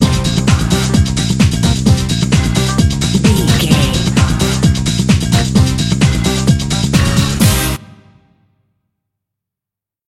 Aeolian/Minor
synthesiser
drum machine
90s
Eurodance